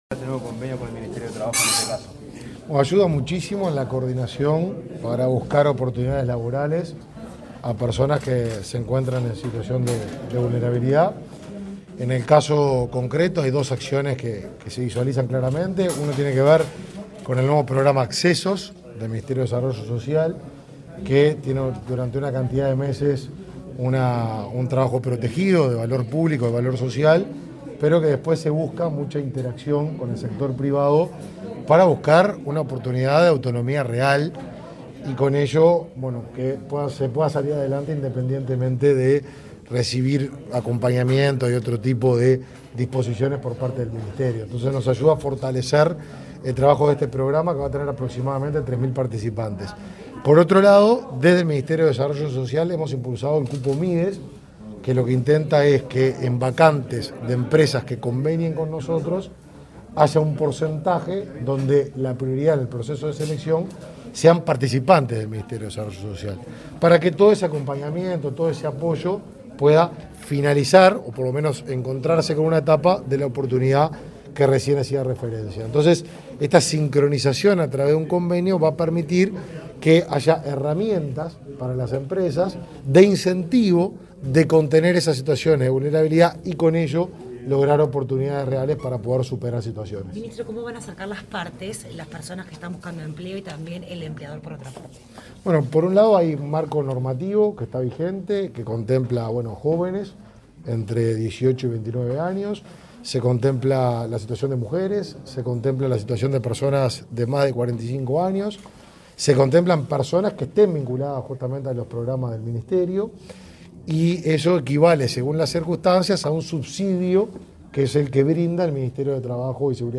Declaraciones del ministro de Desarrollo Social, Martín Lema, a la prensa
Declaraciones del ministro de Desarrollo Social, Martín Lema, a la prensa 01/04/2022 Compartir Facebook X Copiar enlace WhatsApp LinkedIn Tras participar en la firma de un convenio entre el Ministerio de Desarrollo Social y el de Trabajo y Seguridad Social, este 1 de abril, el ministro Martín Lema efectuó declaraciones a la prensa.